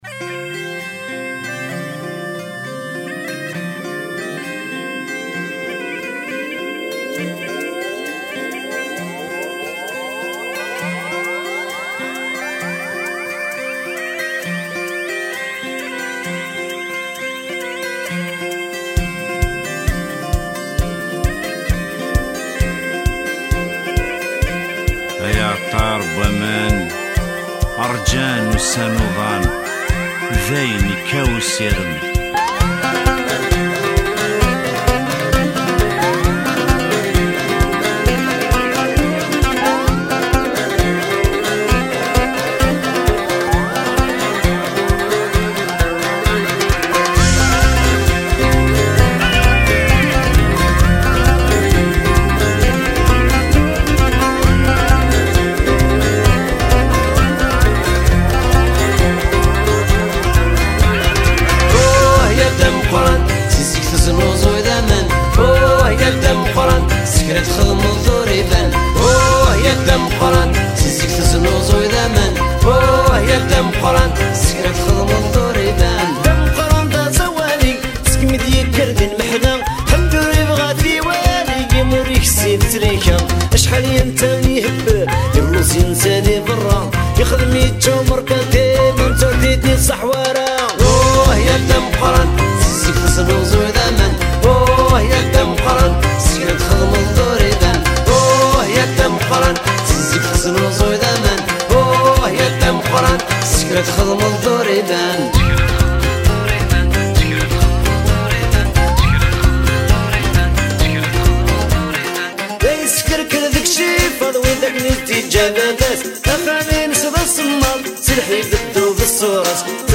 music world